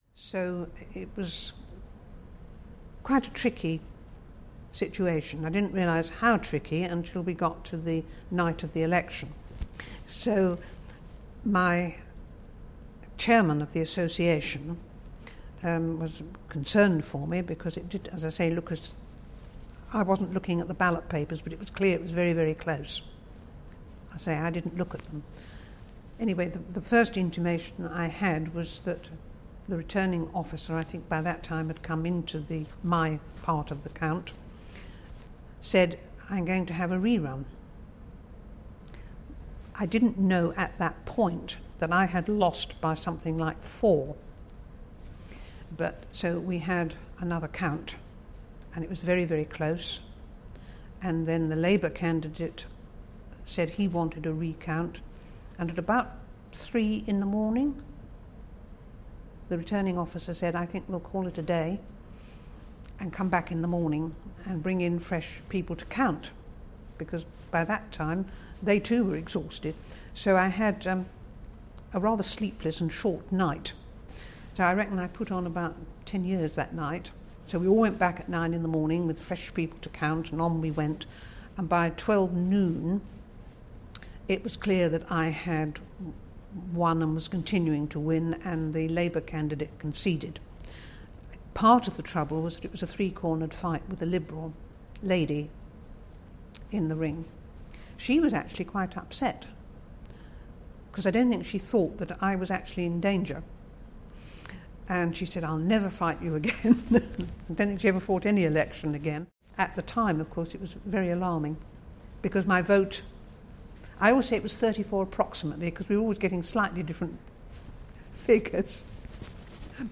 In the first in our 2017 election campaign series, we take a look back at the two elections of 1974 through the memories of our oral history project interviewees…